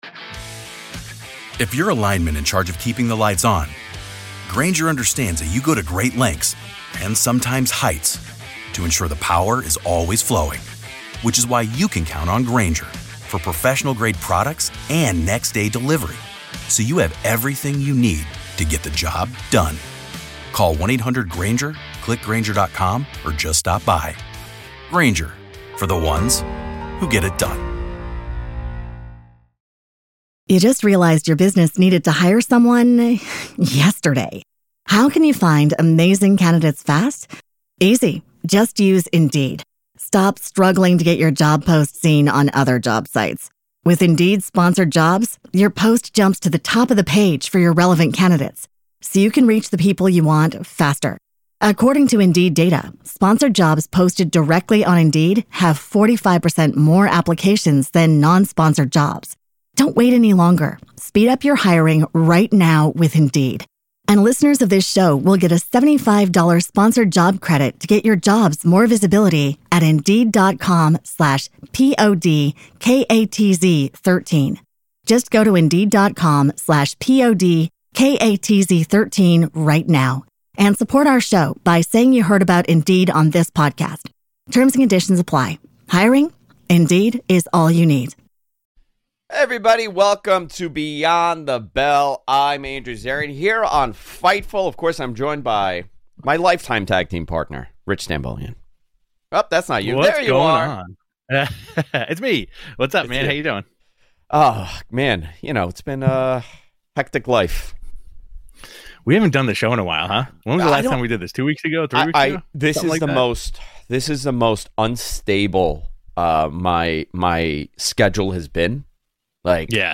Download - Sin Cara On Backstage Fights With Chris Jericho, Sheamus, Simon Gotch | Cinta De Oro Interview | Podbean